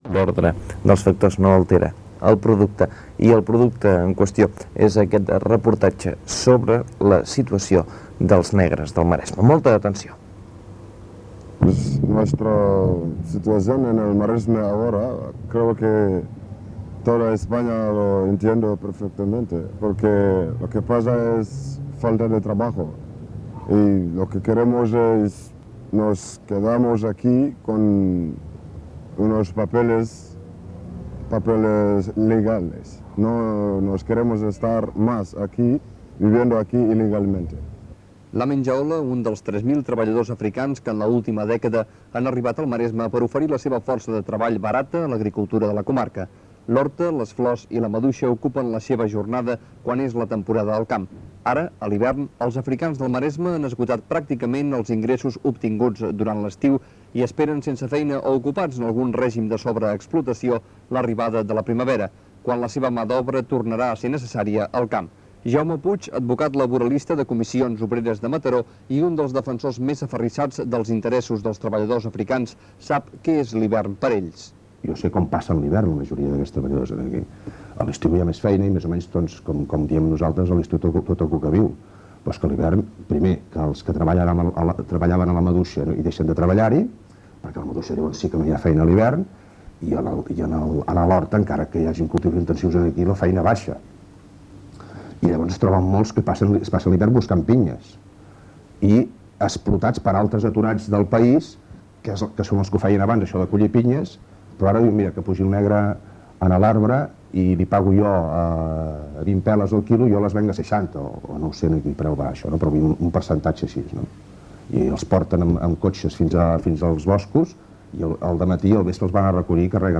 Primera part del reportatge sobre la situació dels treballadors negres del Maresme
Info-entreteniment